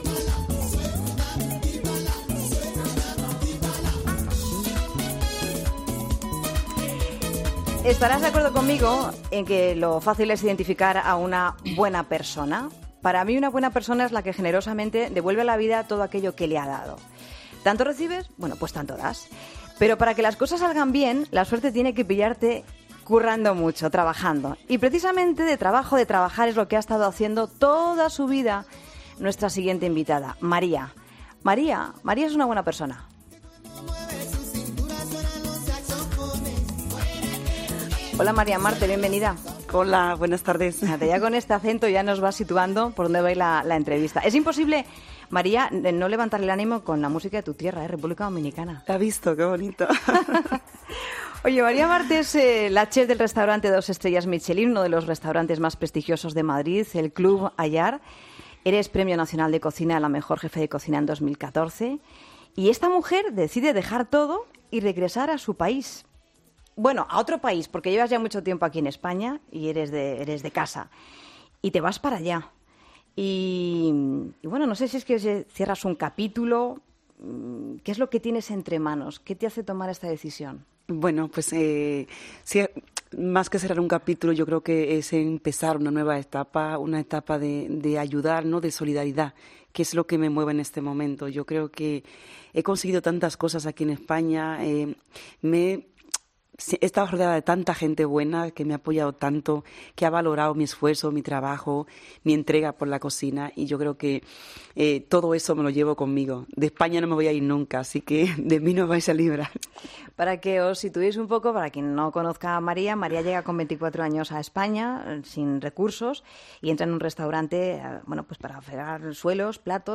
ESCUCHA LA ENTREVISTA COMPLETA | María Marte en 'La Tarde'